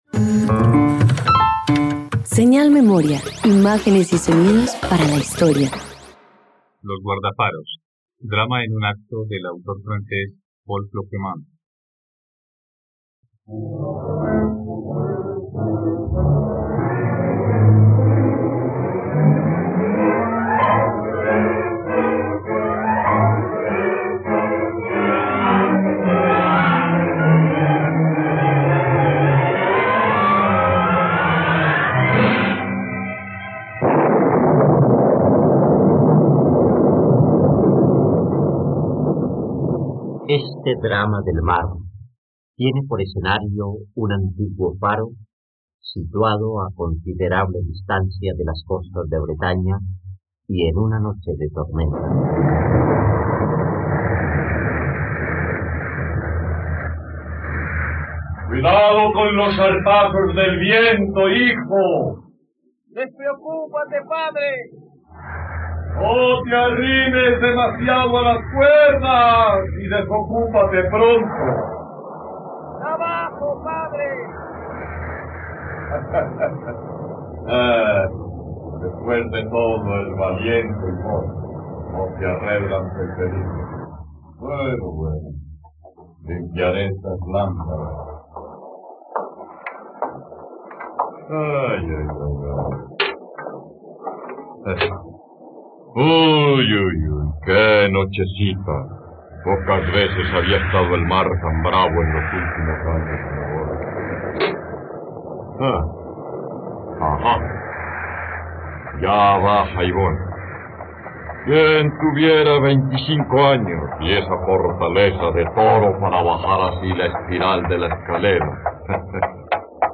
..Radionovela. Escucha ahora Los guardafaros, obra original del autor francés Paul Cloquemaun, en la plataforma de streaming de los colombianos: RTVCPlay.
08_RADIOTEATRO_LOS_GUARDAFAROS.mp3